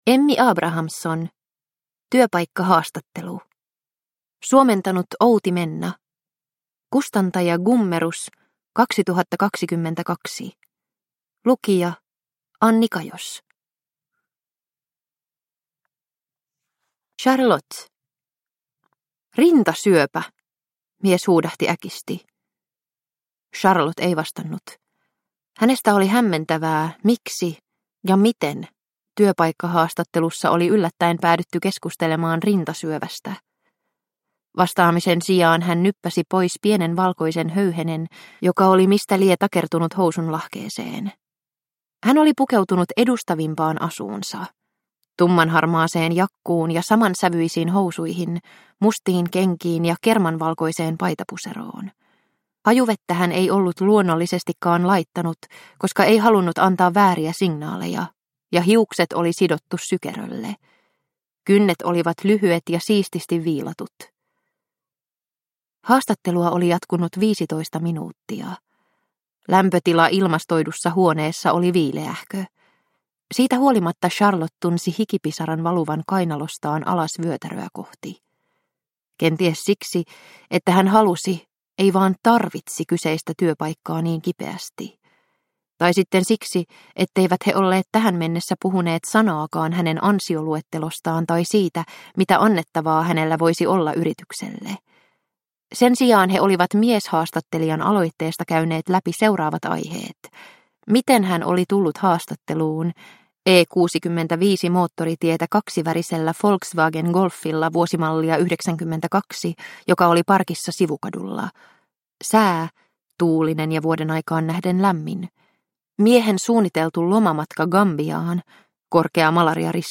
Työpaikkahaastattelu – Ljudbok – Laddas ner